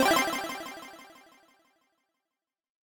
game_start.mp3